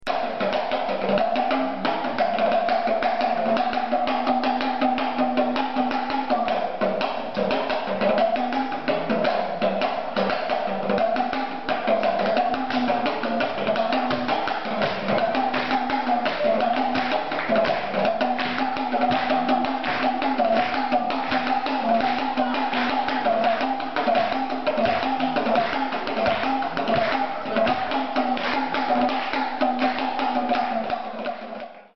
talking drumTalking drums
The talking drum is a West African drum whose pitch can be regulated to the extent that it is said the drum "talks". The player puts the drum under one shoulder and beats the instrument with a stick. A talking drum player raises or lowers the pitch by squeezing or releasing the drum's strings with the upper arm.
talking drum.mp3